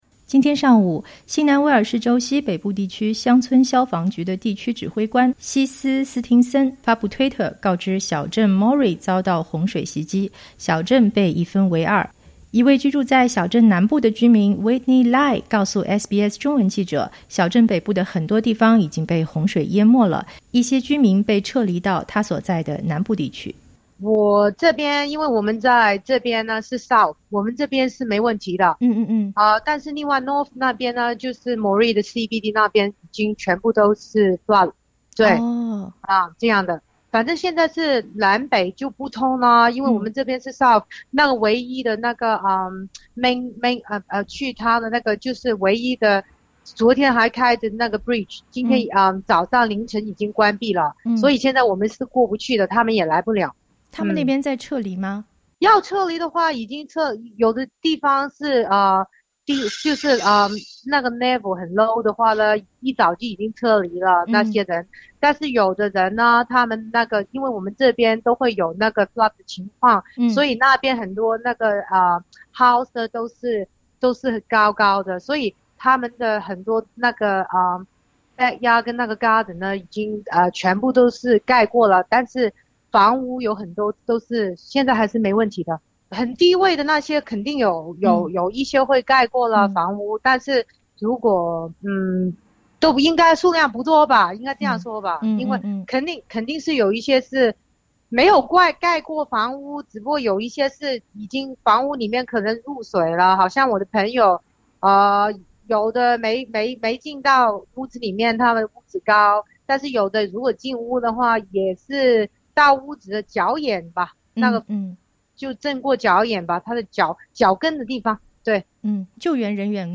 【东海岸洪水】“洪水到来前我们就做好了准备”，Moree居民讲述救援工作